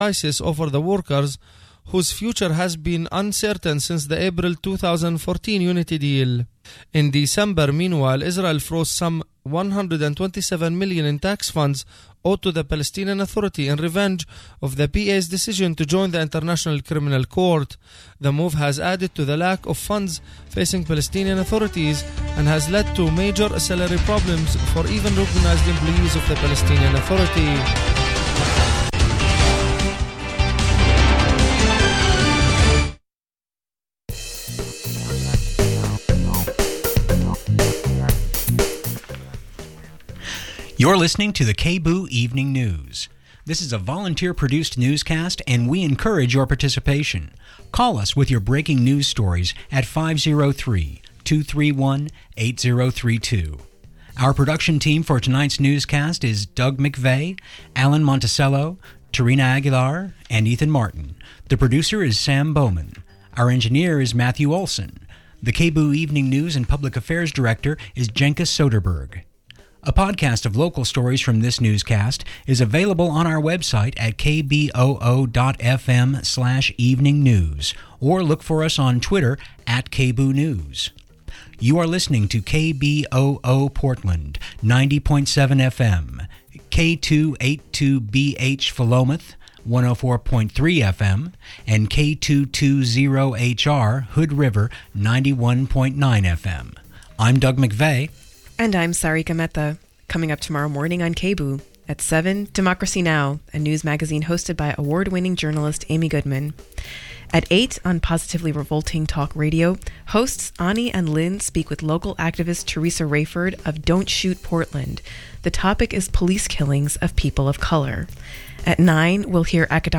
Download audio file On Saturday September 12th, Kshama Sawant spoke in Portland. Here is a recording of that talk.